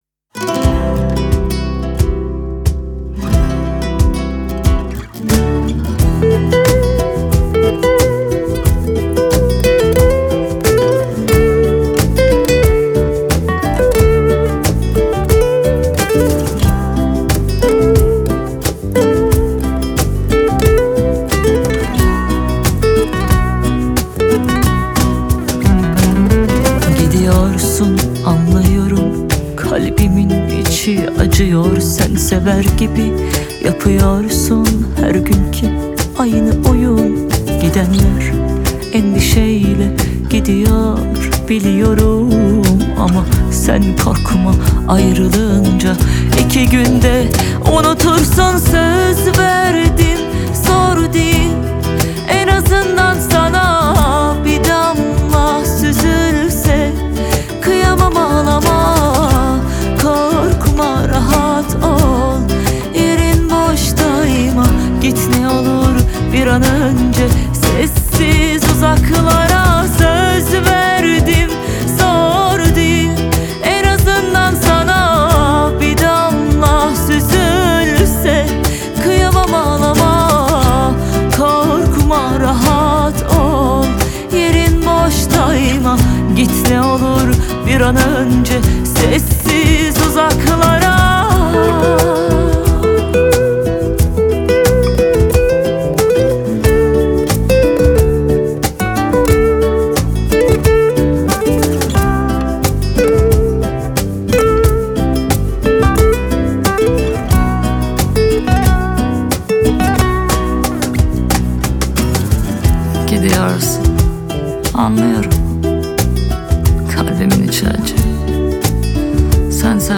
دانلود آهنگ با صدای زن